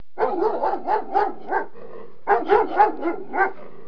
جلوه های صوتی
دانلود صدای حیوانات جنگلی 87 از ساعد نیوز با لینک مستقیم و کیفیت بالا